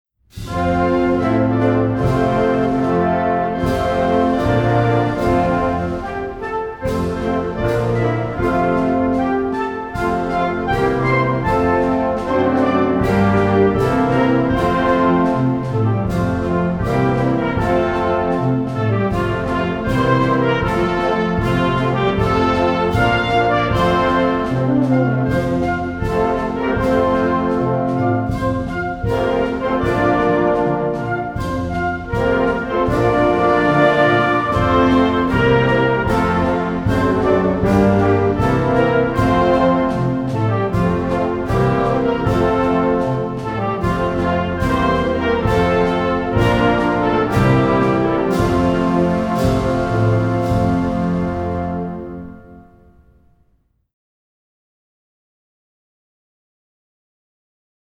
เพลงมหาชัย (วงโยวาทิต)